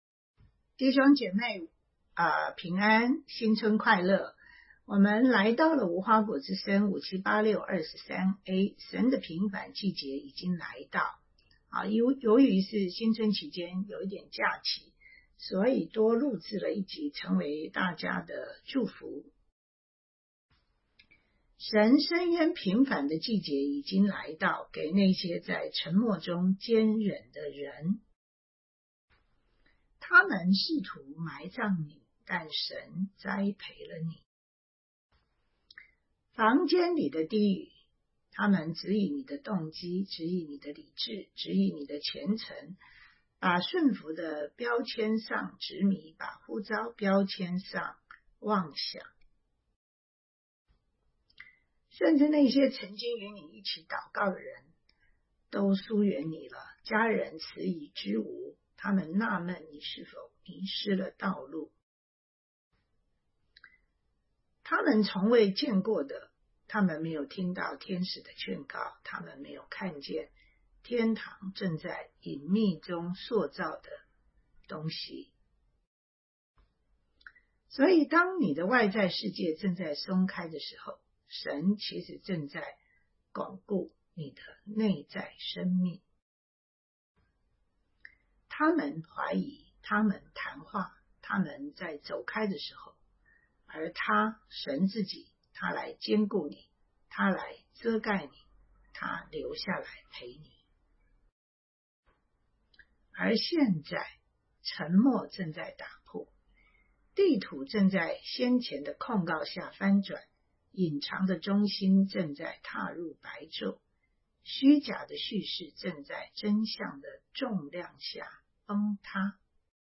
先知性信息5786-23a